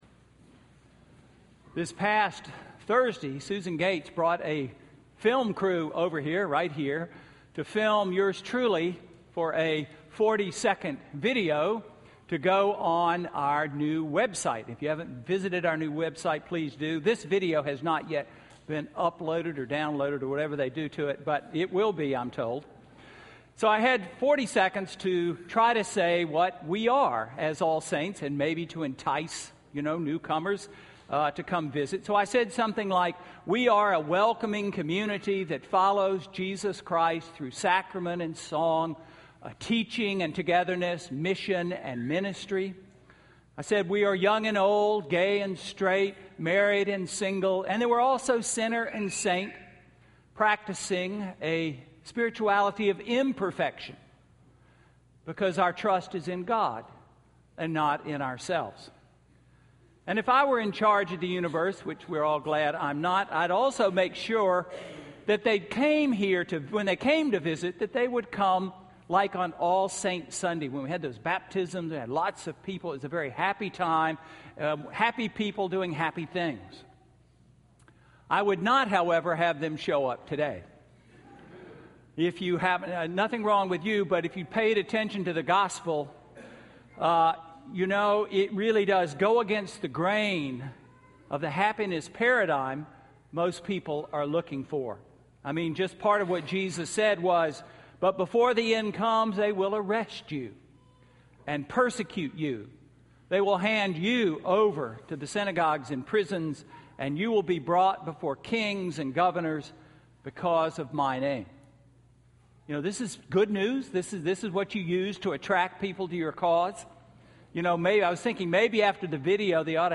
Sermon–November 17, 2013